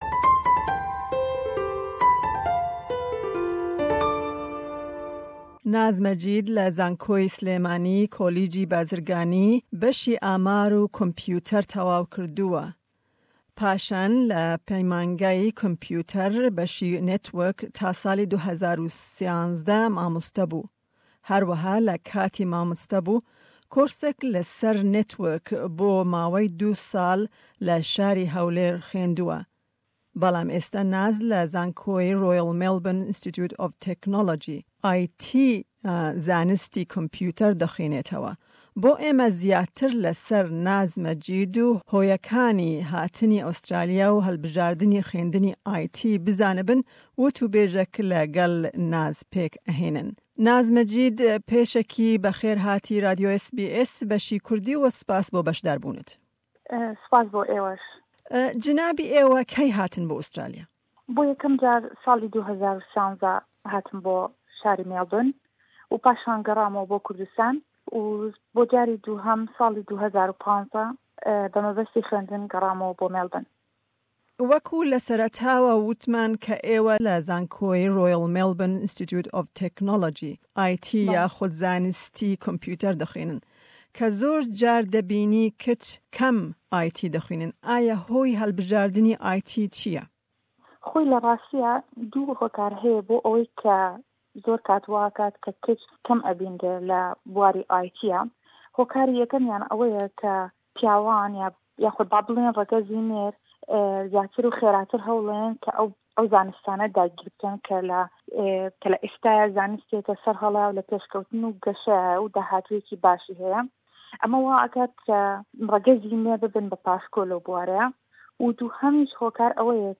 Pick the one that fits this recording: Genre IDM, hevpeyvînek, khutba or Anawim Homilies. hevpeyvînek